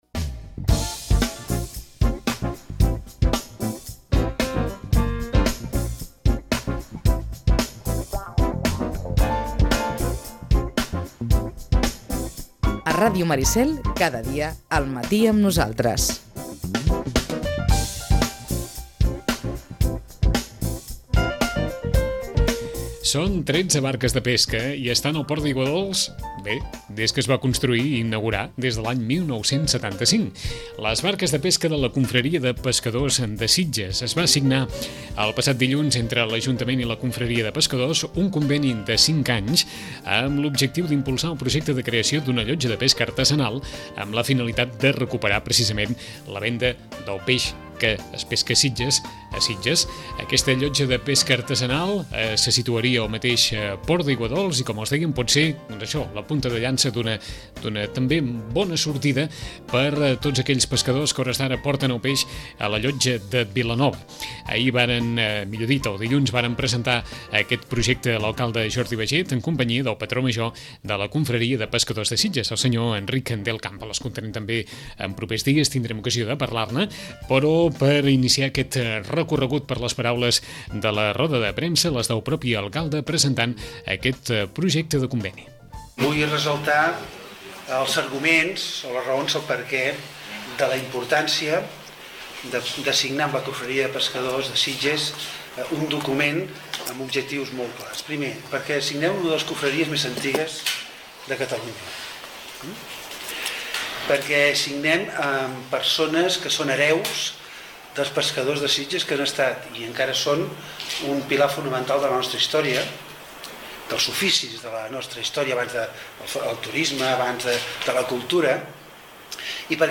En roda de premsa, l’Ajuntament i la confraria de pescadors amb presentat el conveni per a impulsar el projecte de creació d’una llotja de pesca artesanal al port d’Aiguadolç.